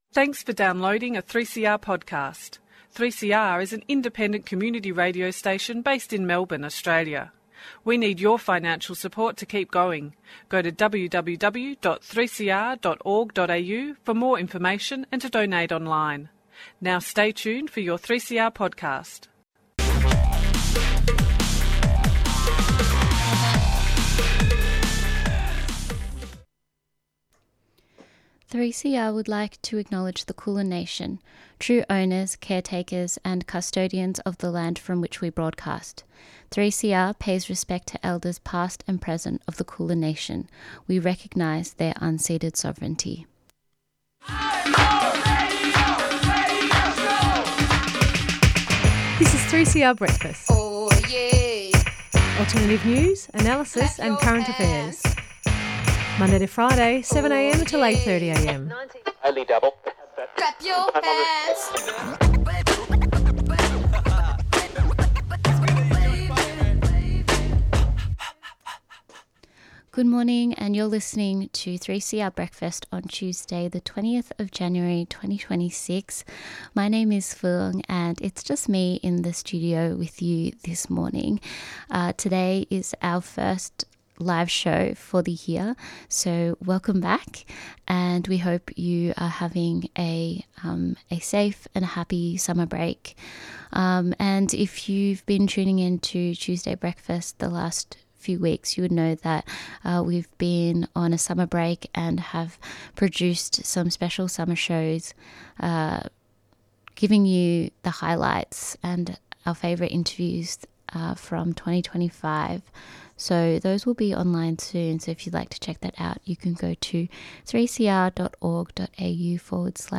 She spoke at the 2025 Tunnerminnerwait & Maulboyheenner commemoration. 3CR will broadcast live from the Tunnerminnerwait & Maulboyheenner Memorial on the corner of Victoria St & Franklin St, Melbourne - the site where these two Aboriginal freedom fighters were executed 184 years ago.